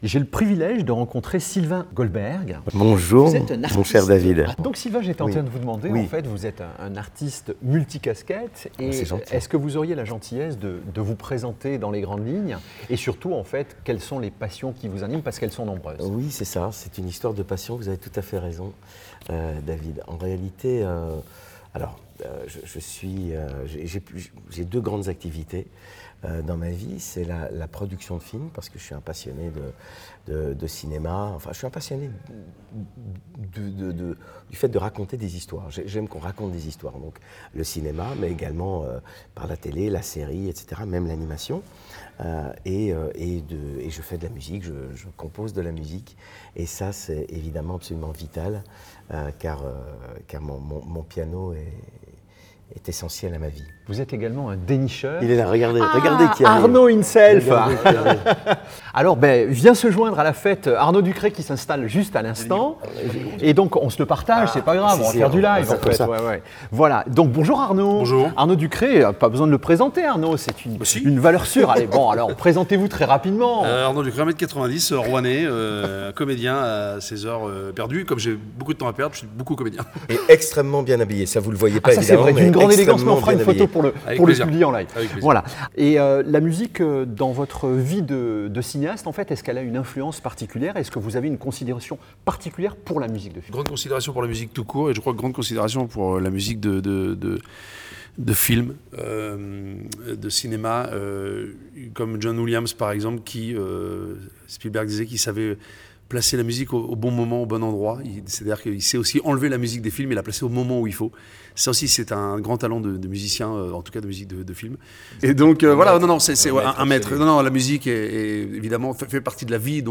Cet entretien révèle leurs passions, leurs projets et leurs réflexions sur le cinéma et la musique de film.